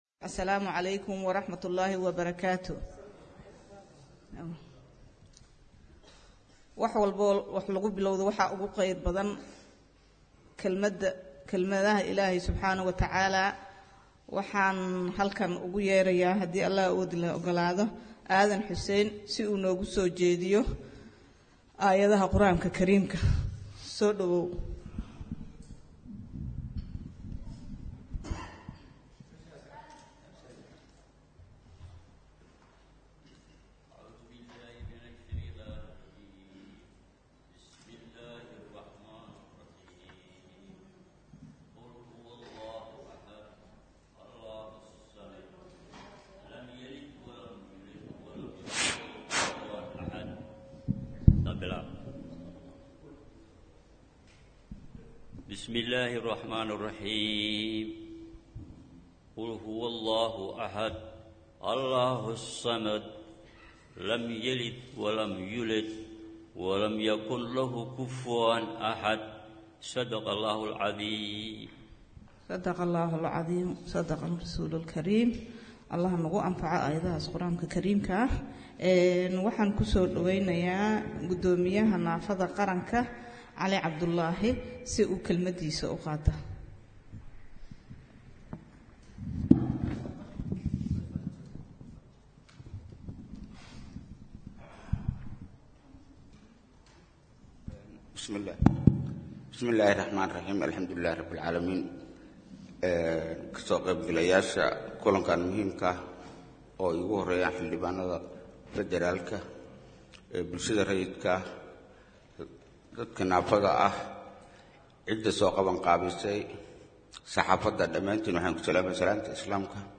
Akhristayaasheena sharafta leh waxaan halkan idinkugu soo gudbineynaa Dood Furan oo ku saabsan Xeerka Dhismaha Hay’adda Naafada Qaranka Oo Lagu Qabtay Muqdisho.
DOOD-FURAN-KULAN-LOOGA-HADLAYEY-XEERKA-DHISMAHA-HEYADDA-NAAFADA-QARANKA-OO-MUQDISHO-LAGU-QABTAY..mp3